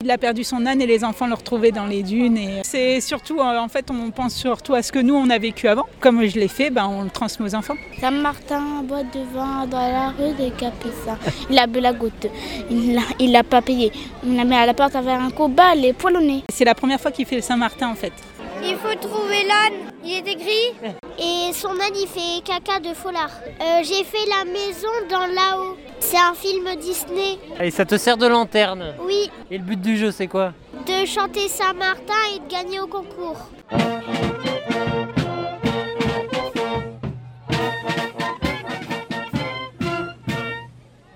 Des centaines de parents et enfants ont suivi Saint-Martin dans les allées du Château Coquelles à Rosendaël mardi soir.
L'animal tout gris a été retrouvé en chanson et à la joie des tous petits.